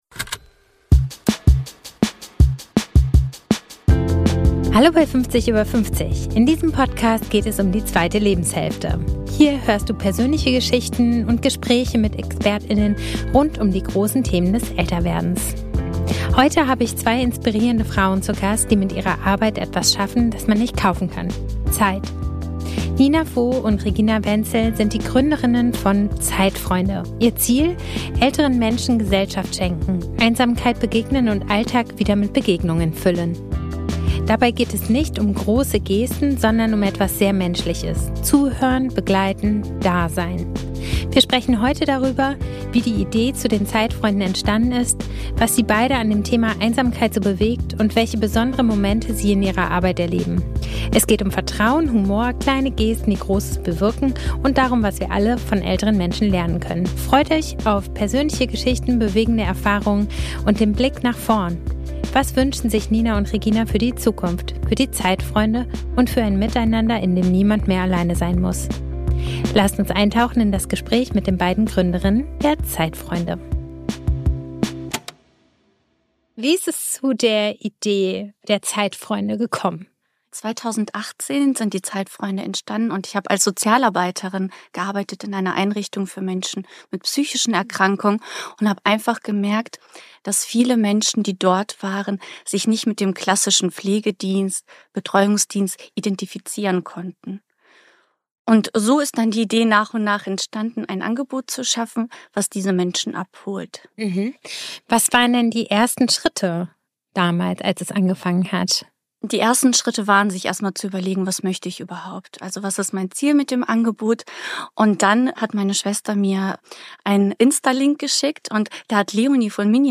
Hier hörst du persönliche Geschichten und Gespräche mit ExpertInnen rund um die großen Themen des Älterwerdens. Heute habe ich zwei inspirierende Frauen zu Gast, die mit ihrer Arbeit etwas schaffen, das man nicht kaufen kann: Zeit.